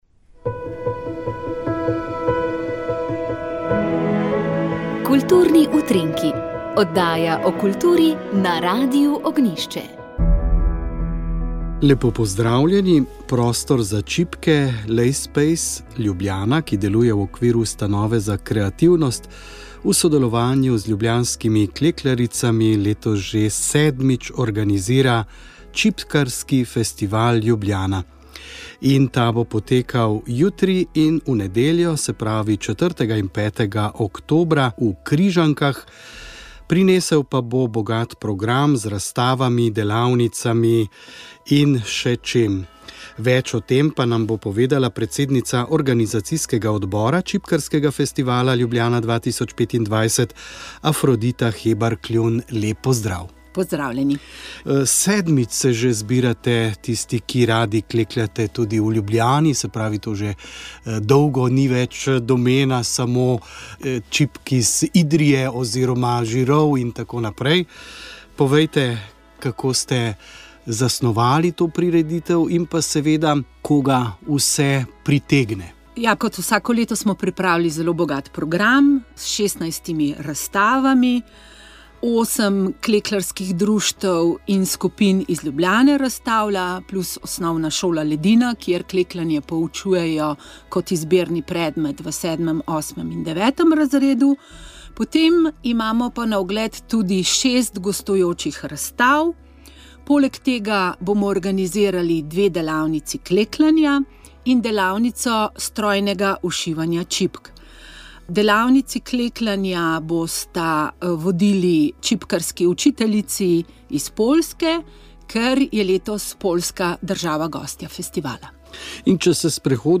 Vremenska napoved